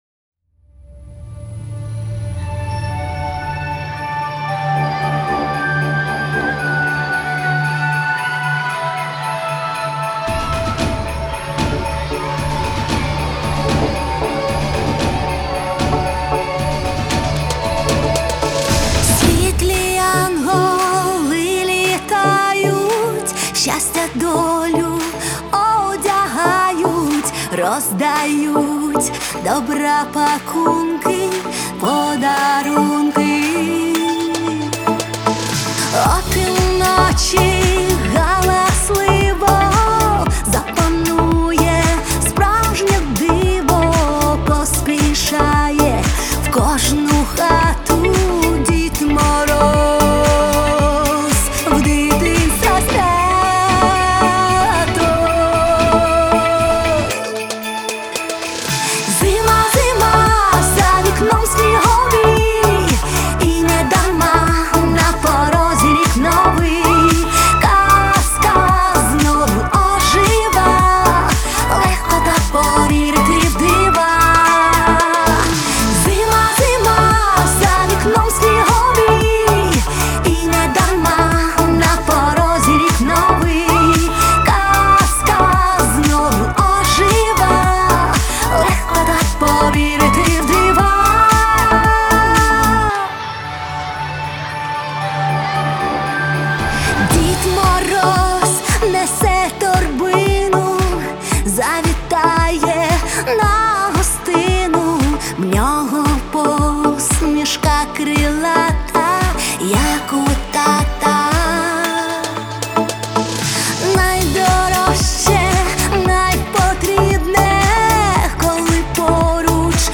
это яркий пример современного поп-музыки